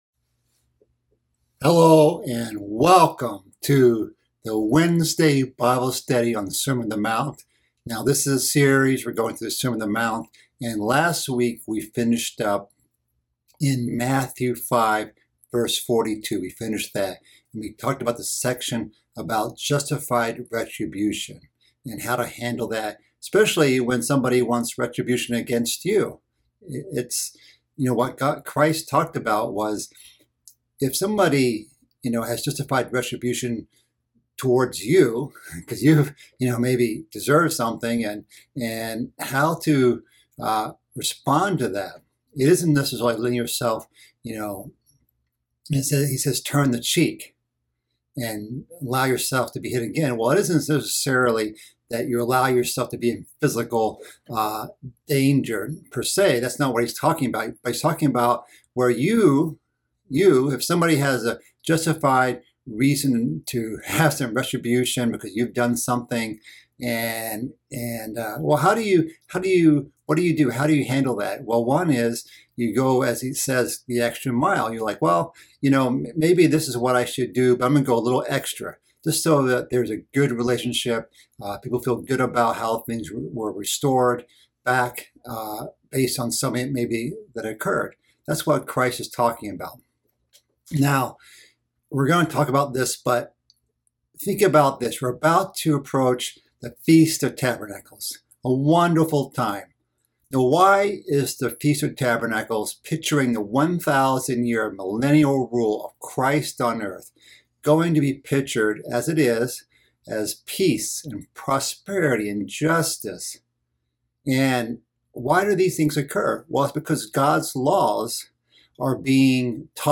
This is part of a mid-week Bible study series covering the sermon on the mount. This week's study covers loving our enemies, the final section of chapter 5.